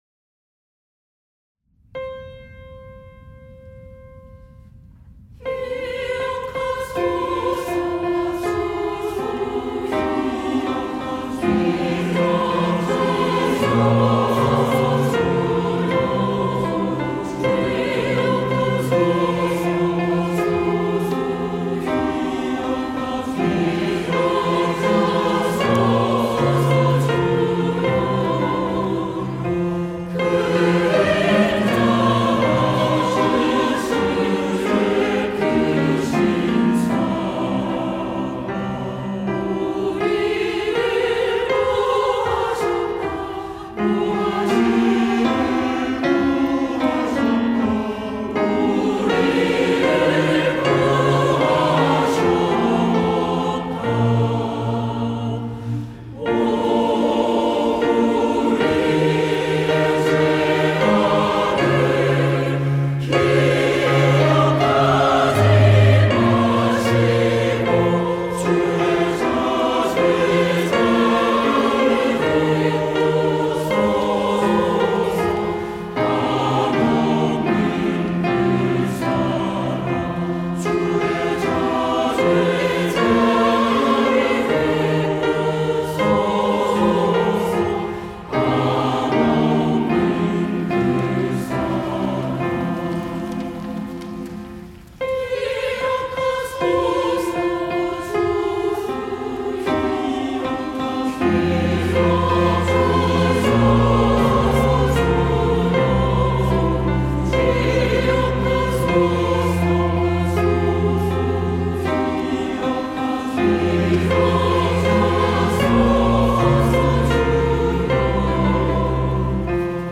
시온(주일1부) - 기억하소서
찬양대